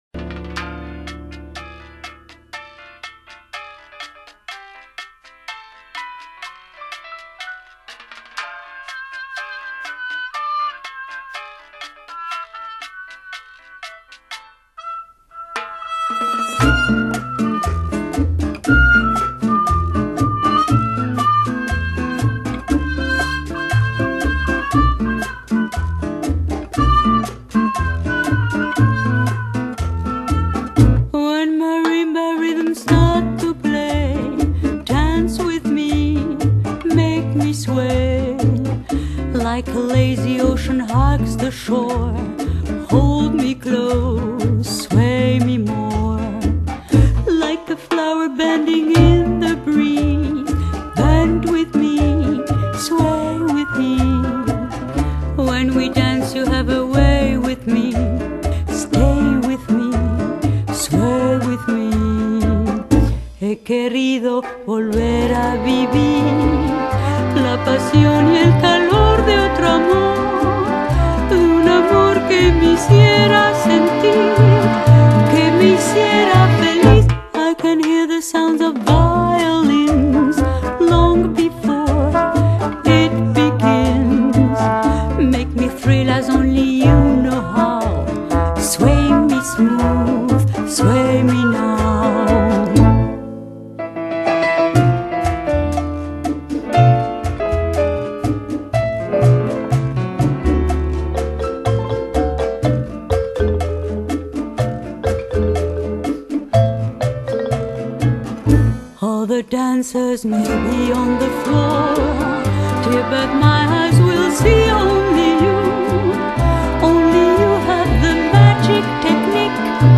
嗓音很有些典型的传统小酒馆(Cabaret)的味道。
里面收录的都是四五十年代的经典Jazz老歌，拉丁风情浓郁。